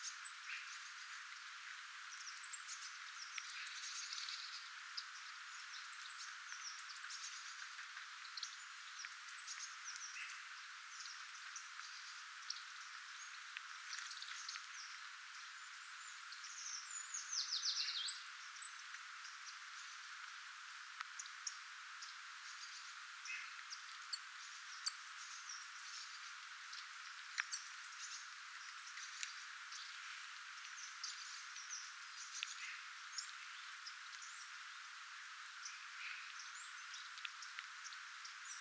vähän erikoisia ääniä
käpytikalla, taustalla?
erikoisia_aania_taustalla_ehkapa_kapytikalta.mp3